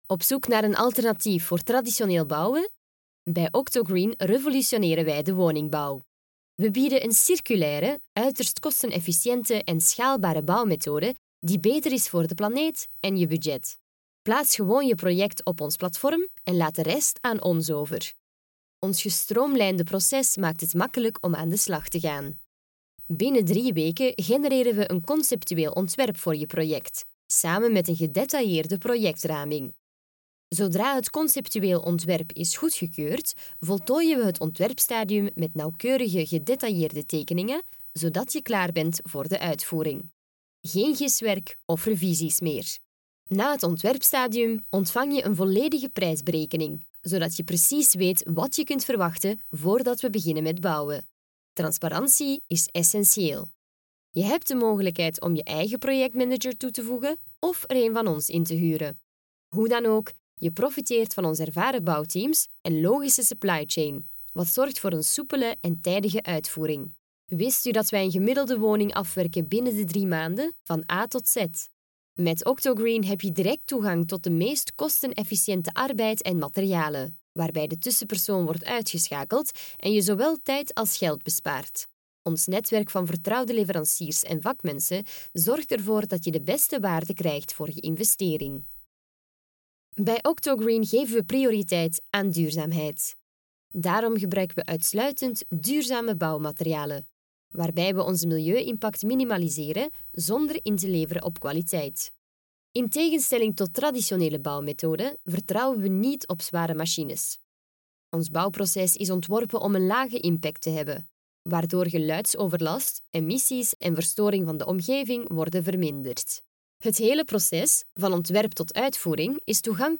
Commercial, Young, Natural, Versatile, Friendly
Corporate